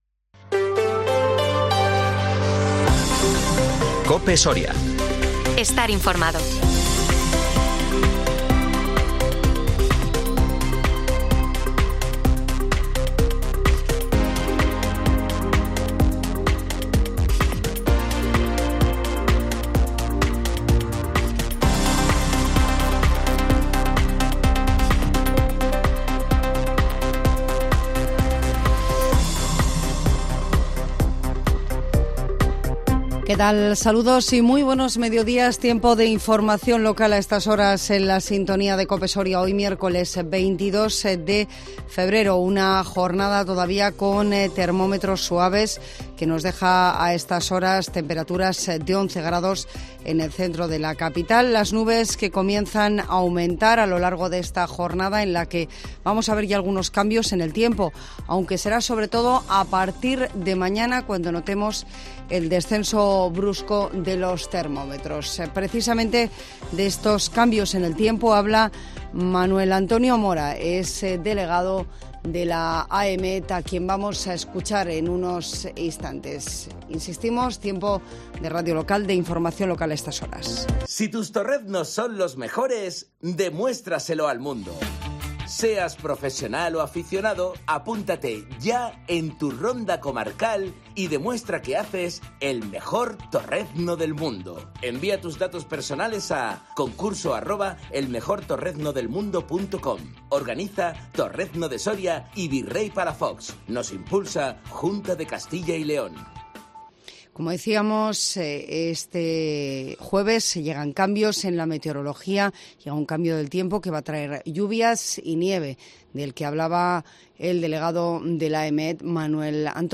INFORMATIVO MEDIODÍA COPE SORIA 22 FEBRERO 2023